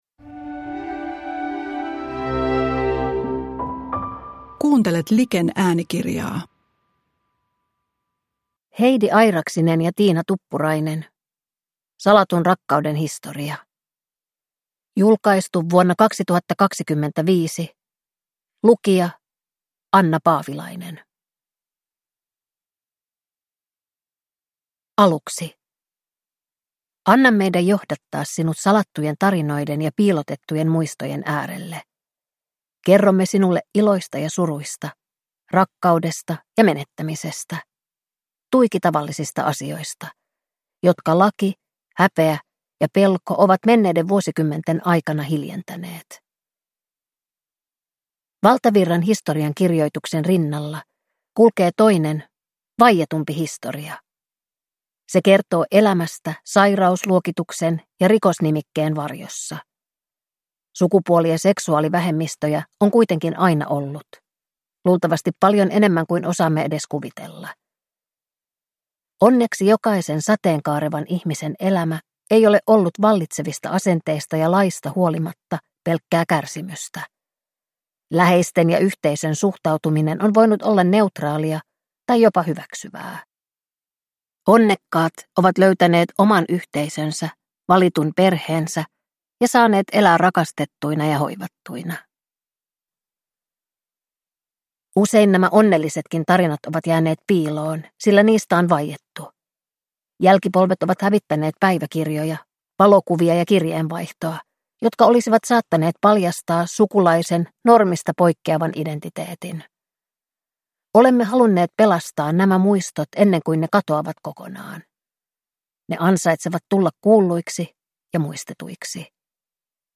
Salatun rakkauden historia – Ljudbok